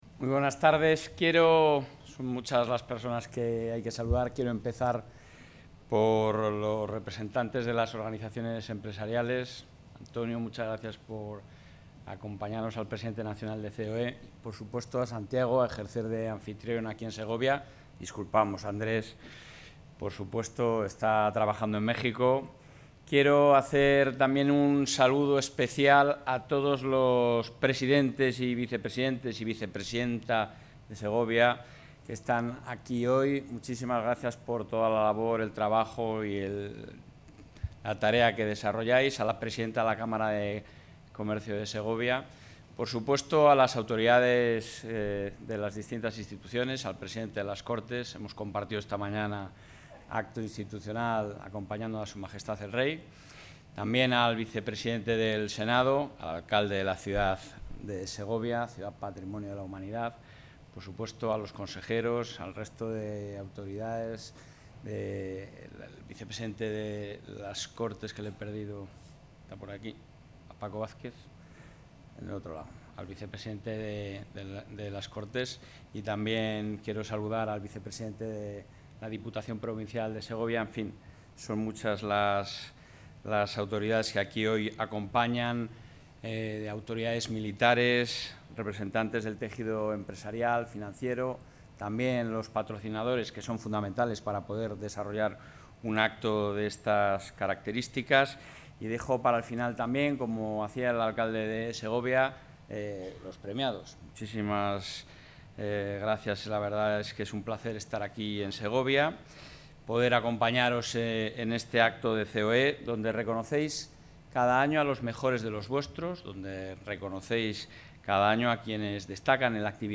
Intervención del presidente de la Junta.
El presidente de la Junta de Castilla y León, Alfonso Fernández Mañueco, ha participado esta tarde, en Segovia, en la entrega de Premios CEOE Castilla y León 2023, donde ha agradecido la labor que realizan los empresarios, junto a sus trabajadores, en favor del desarrollo económico y el progreso de Castilla y León, ya que contribuyen a la creación de riqueza y empleo.